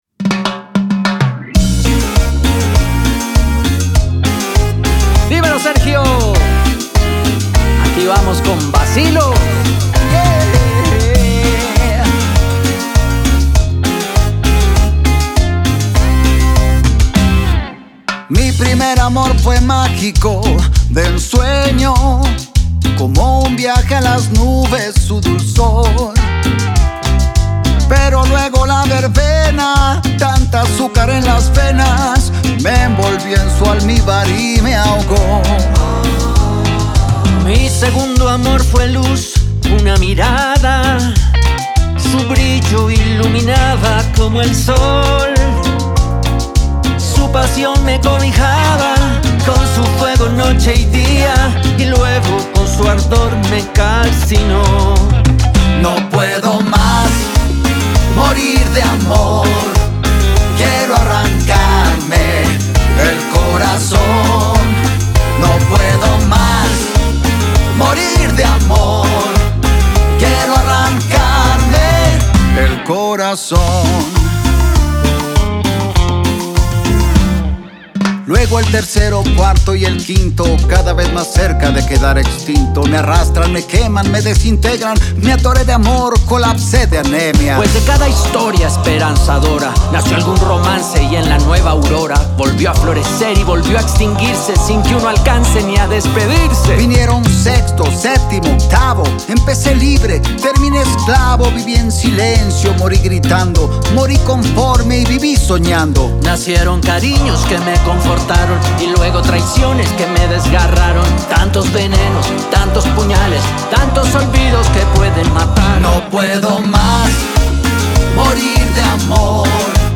batería
percusión
guitarra
teclados